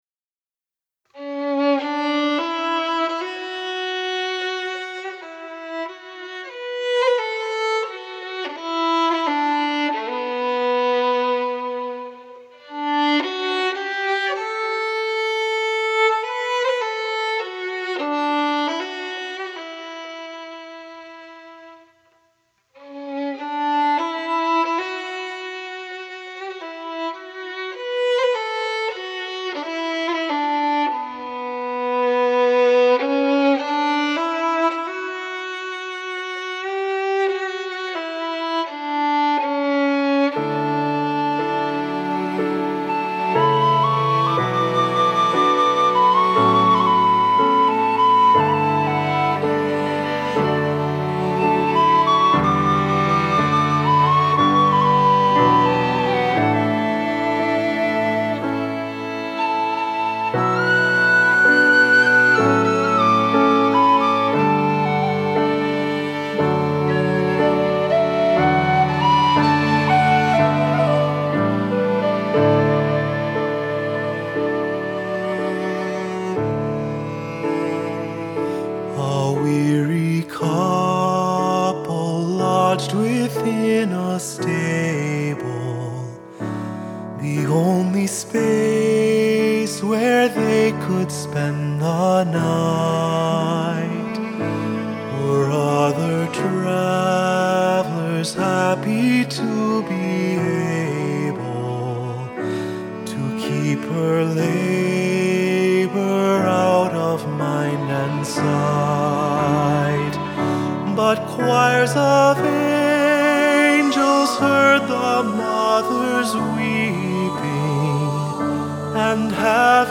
Accompaniment:      Keyboard, Solo
Music Category:      Choral